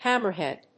音節hámmer・hèad
アクセント・音節hámmer・hèad